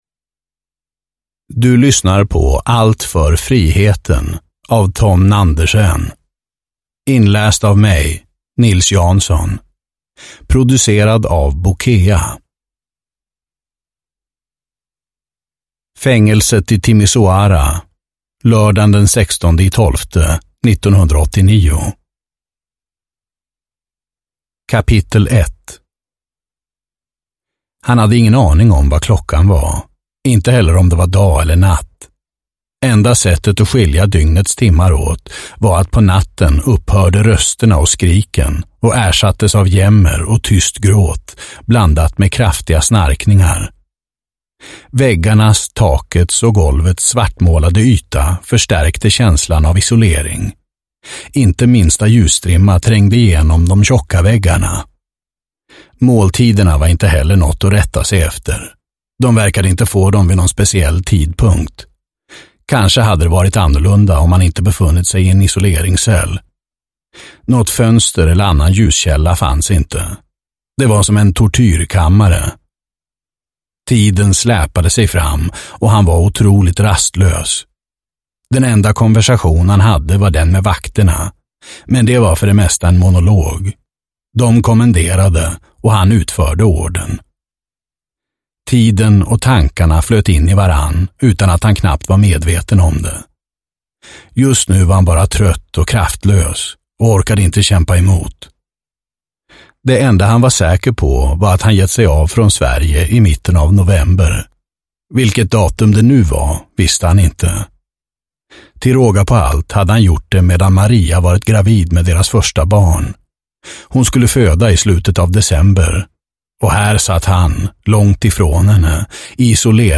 Allt för friheten – Ljudbok